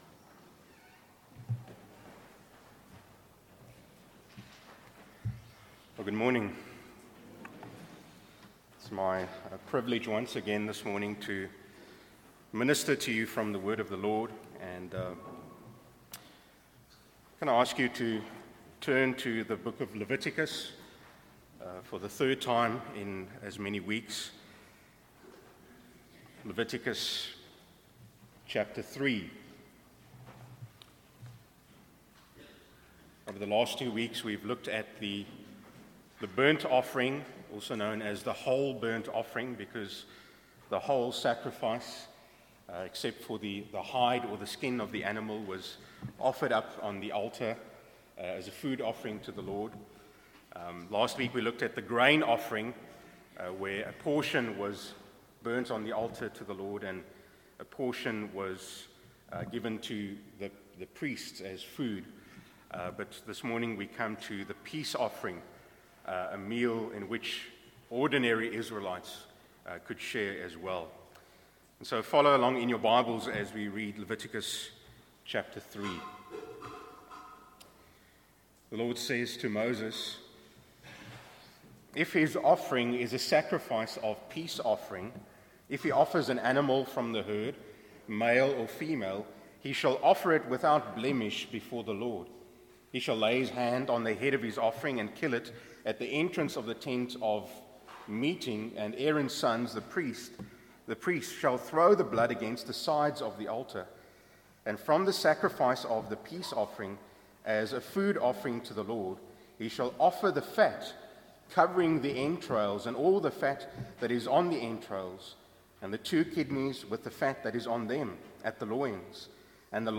A message from the series "The Gospel in Leviticus."